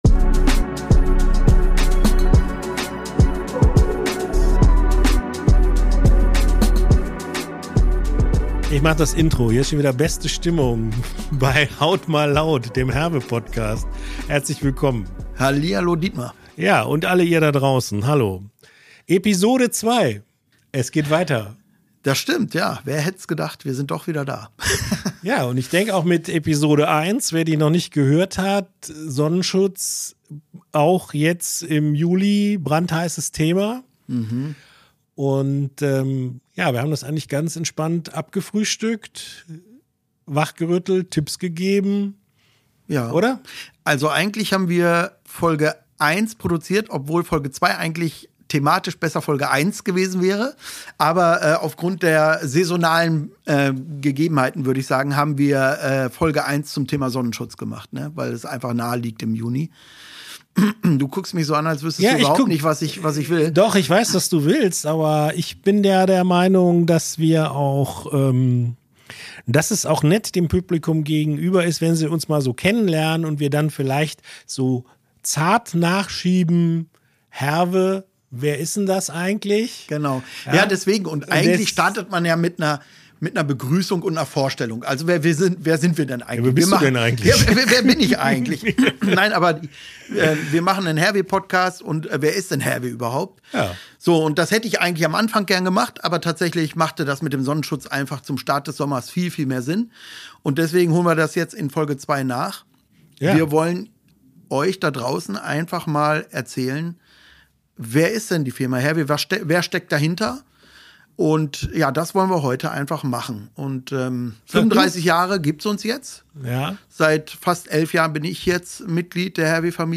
Entspannter Talk über HERWE, ein Unternehmen in dessen Fokus schon immer der richtige Hautschutz stand!